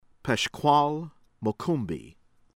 MACHEL, SAMORA MOISES sah-MOHR-ah   moy-ZEHSH   mah-SHEHL